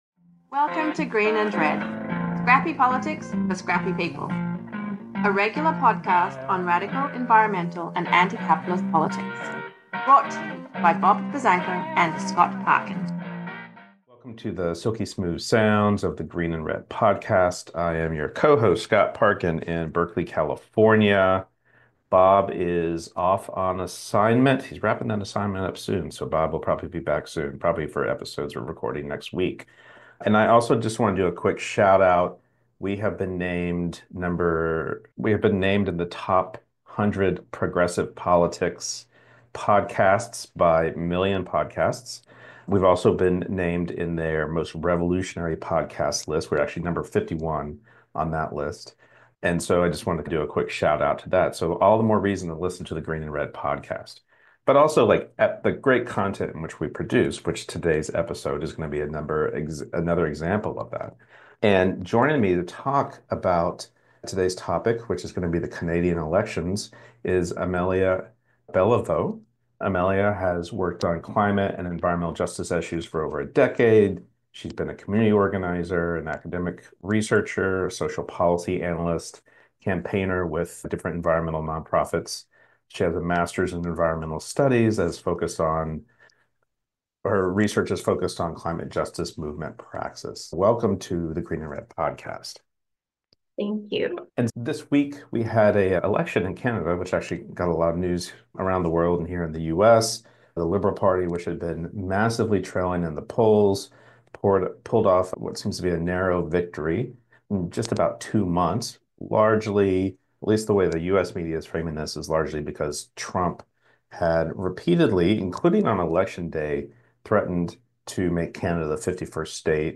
talks with climate campaigner and researcher